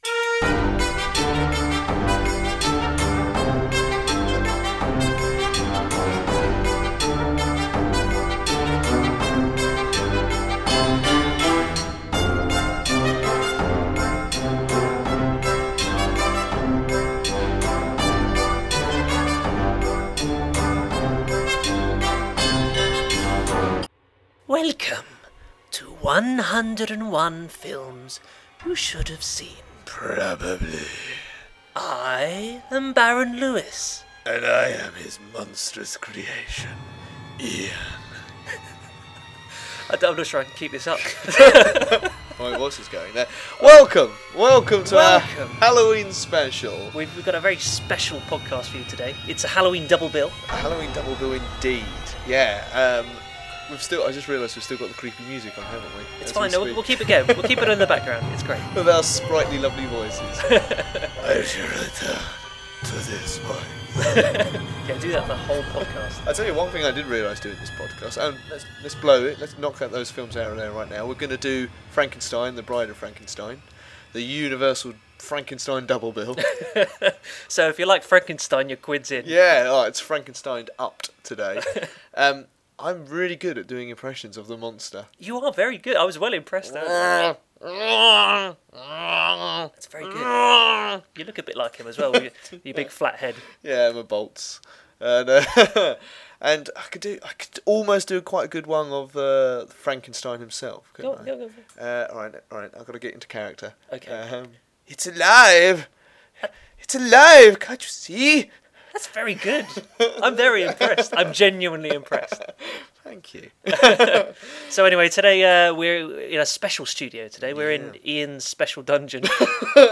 We can assure you that any screaming you can hear in the background is merely a sound effect and should be ignored.